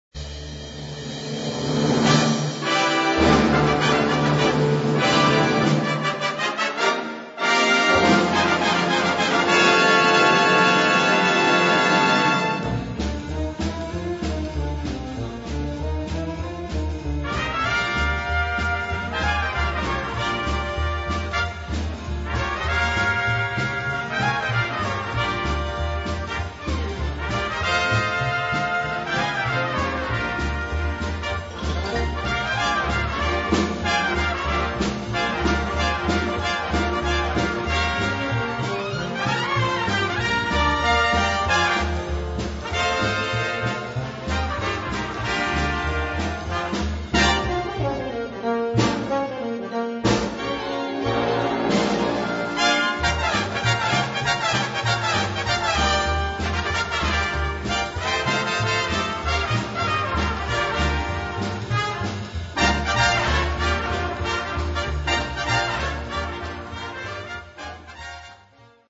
Gattung: Solo für vier Trompeten
Besetzung: Blasorchester